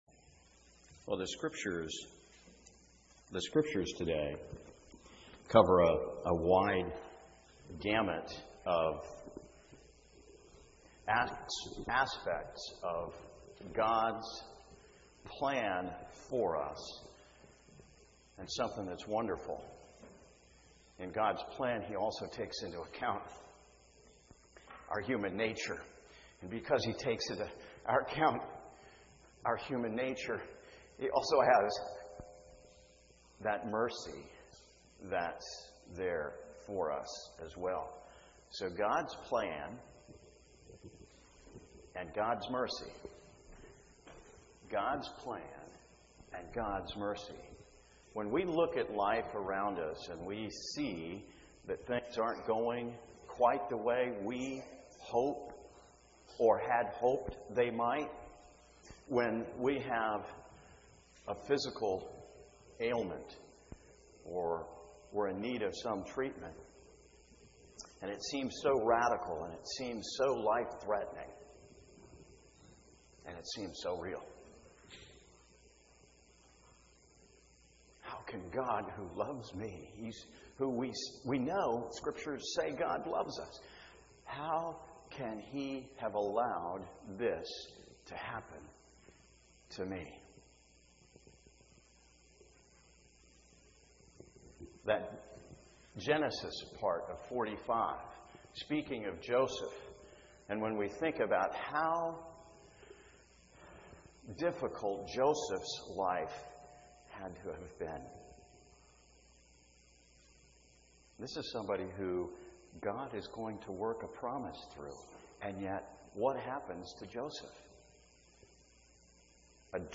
Sermon Aug 17 CUMC